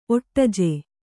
♪ oṭṭaje